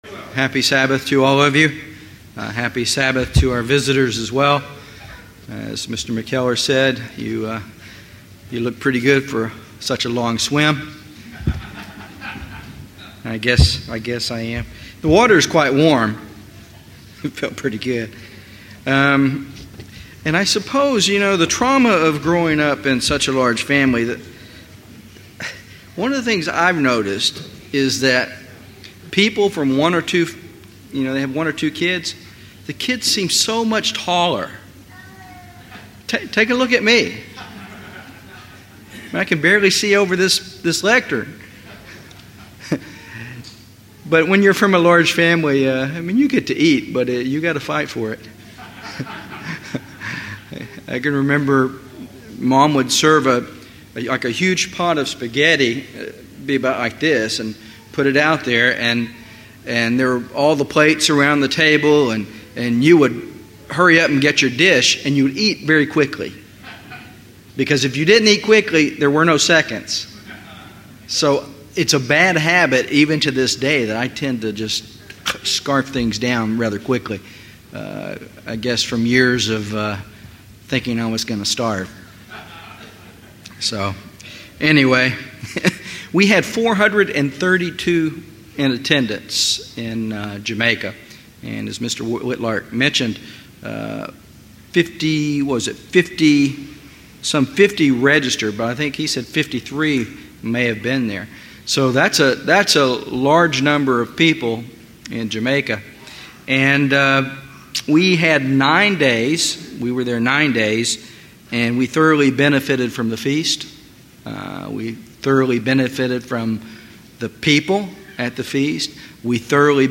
This sermon focuses on loving your neighbor through hospitality.